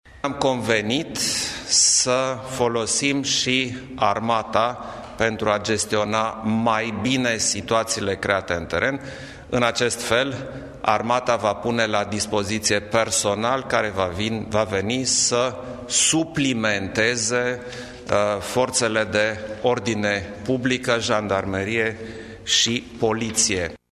O nouă restricție vizează persoanele peste 65 de ani, care vor trebui „să stea practic tot timpul acasă”, a spus șeful statului: